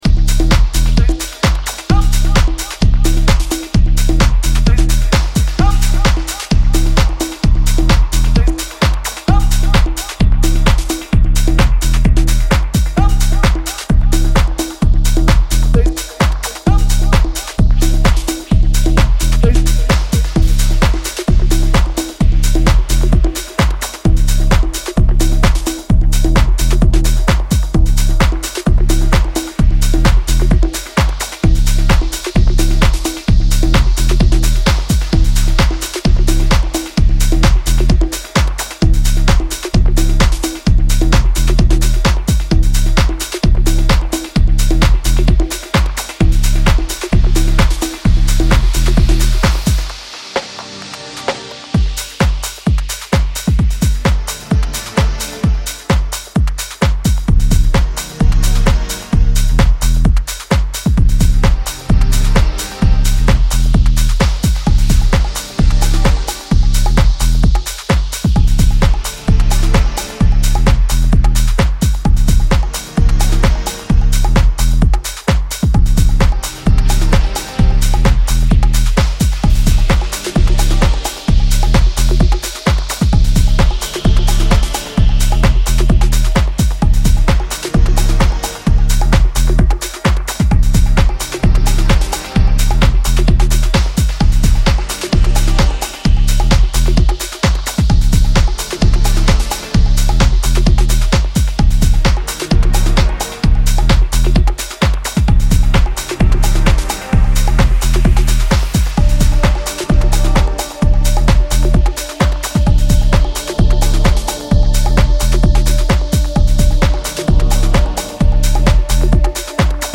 house and garage